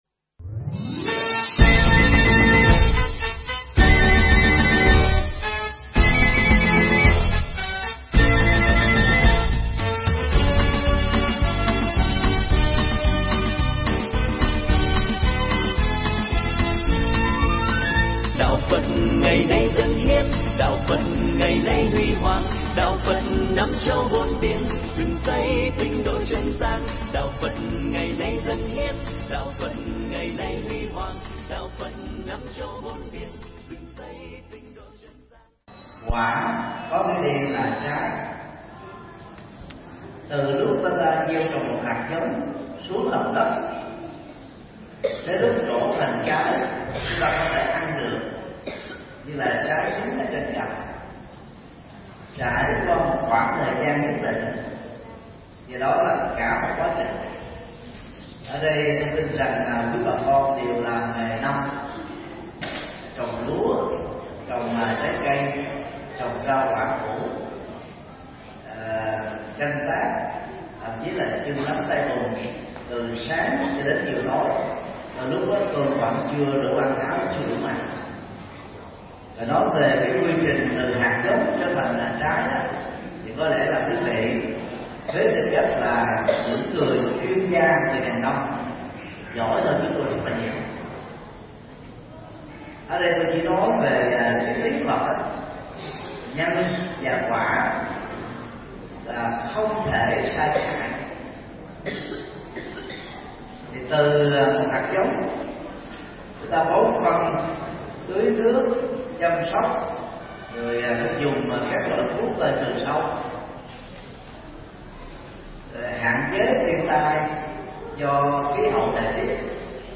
Mp3 Pháp Thoại Bốn Sự Thật Không Thể Phủ Định – Thượng Tọa Thích Nhật Từ giảng tại chùa Quê Hương, Đồng Tháp, ngày 9 tháng 2 năm 2018